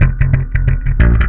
SLAPBASS1 -R.wav